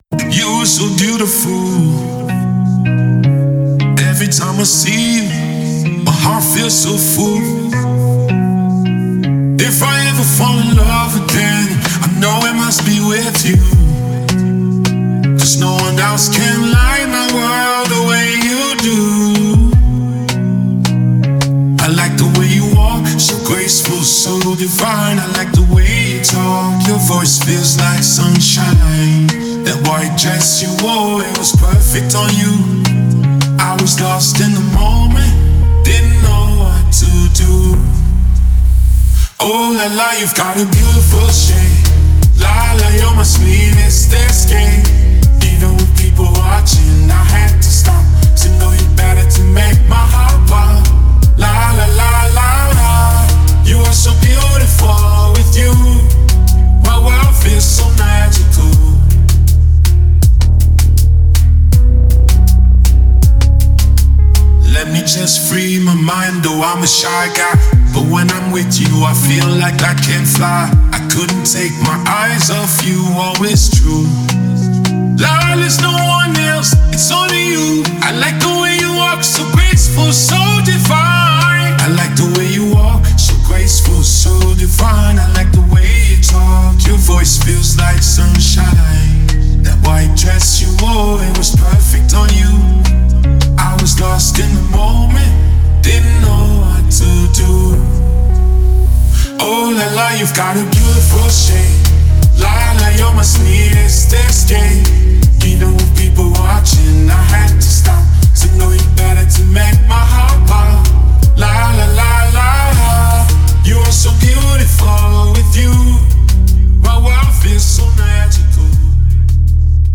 Nigeria Music